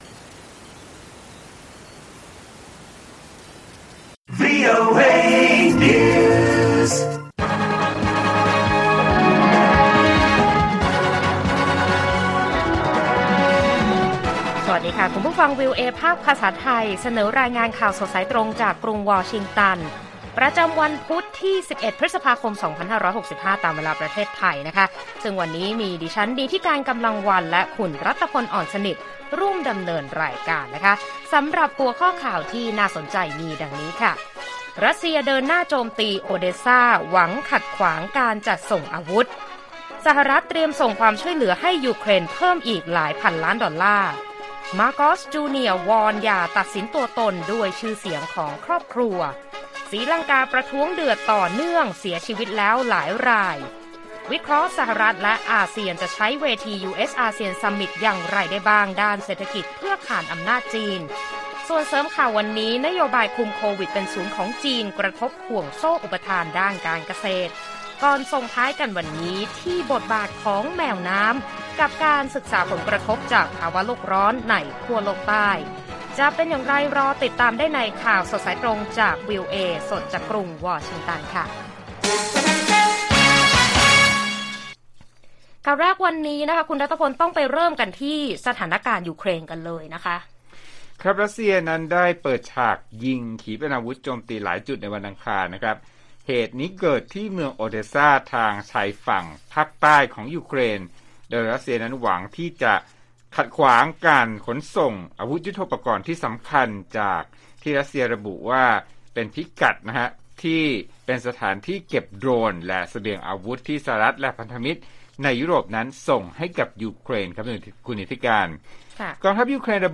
ข่าวสดสายตรงจากวีโอเอไทย 6:30 – 7:00 น. วันที่ 11 พ.ค. 65